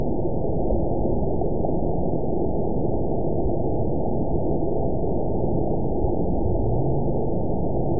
event 920582 date 03/31/24 time 00:49:20 GMT (1 year, 1 month ago) score 9.03 location TSS-AB02 detected by nrw target species NRW annotations +NRW Spectrogram: Frequency (kHz) vs. Time (s) audio not available .wav